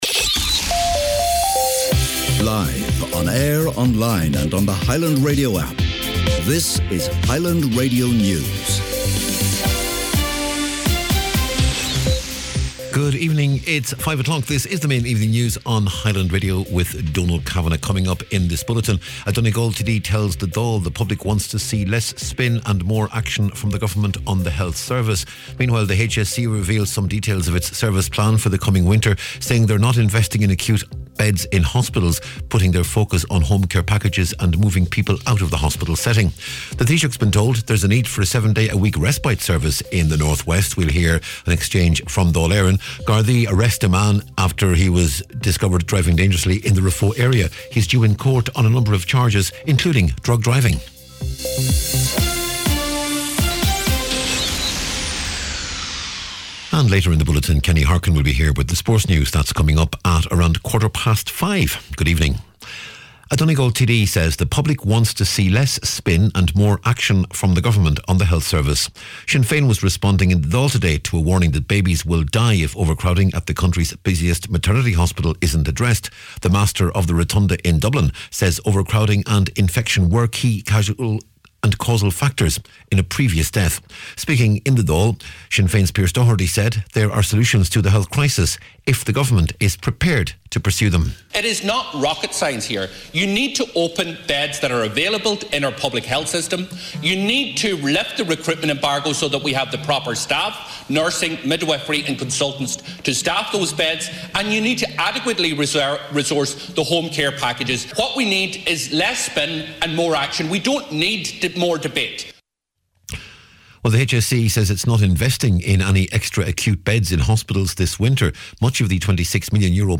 Evening News, Sport and Obituaries on Thursday November 14th